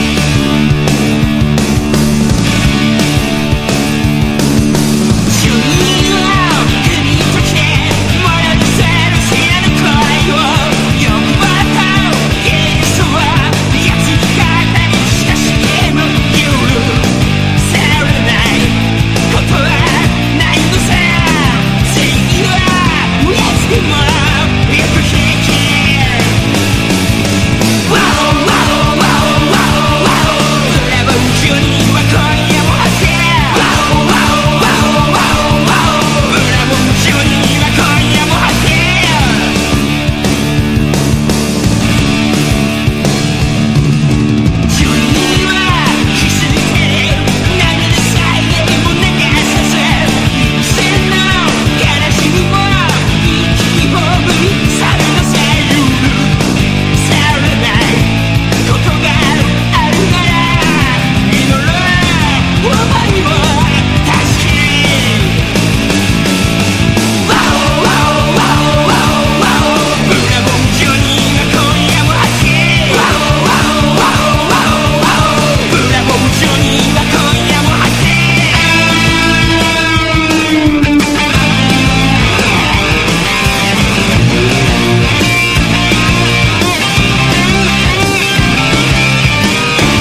60-80’S ROCK